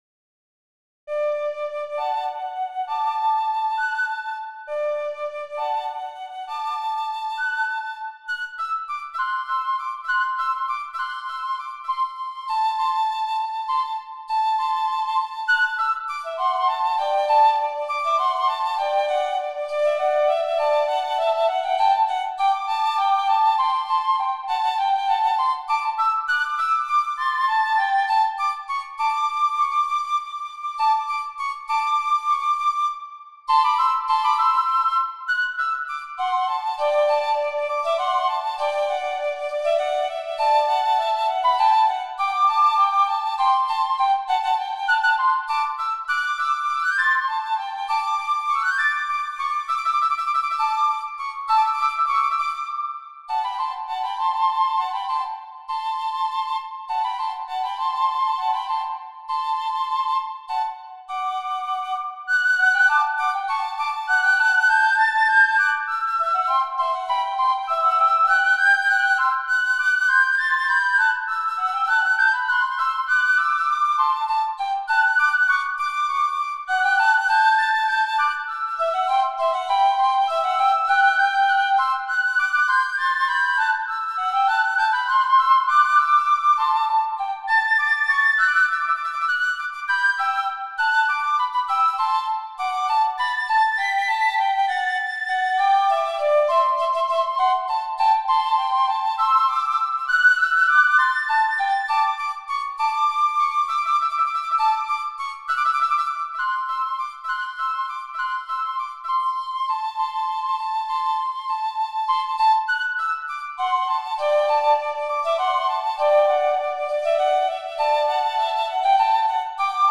Piccolo Duos on Irish Themes